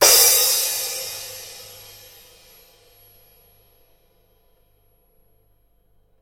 描述：撞击钹